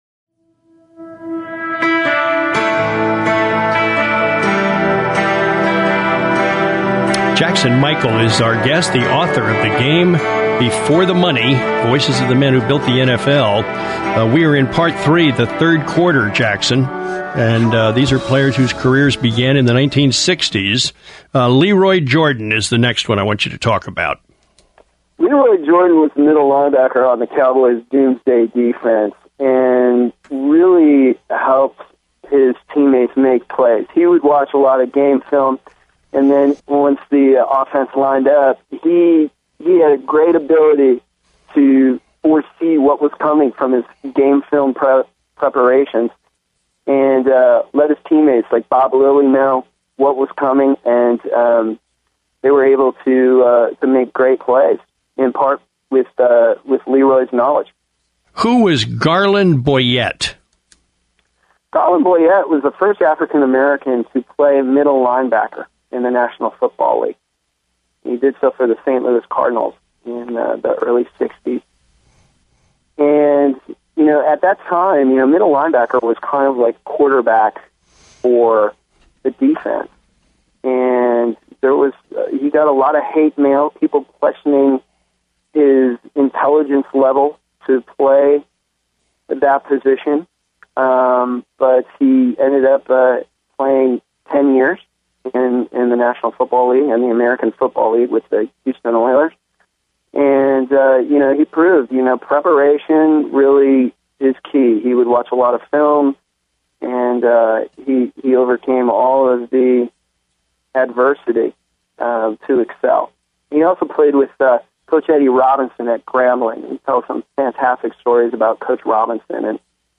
PatWilliamsINterviewPt2.mp3